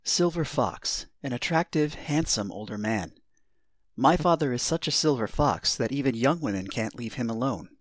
口語では、全く別の意味を持ち、ロマンスグレー （和製英語。英語では Silver- gray hair ）のダンディでセクシーな年配男性を指します。 ネイティブによる発音は下記のリンクをクリックしてください。